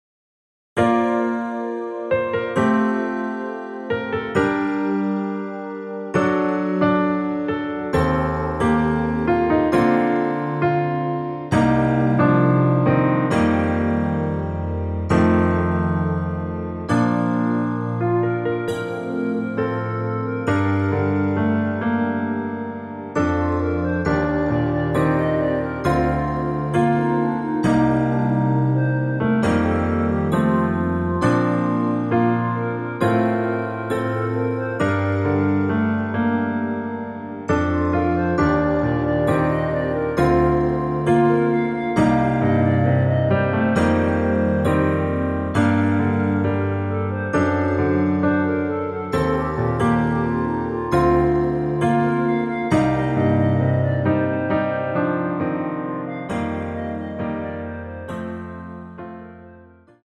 Bb
앞부분30초, 뒷부분30초씩 편집해서 올려 드리고 있습니다.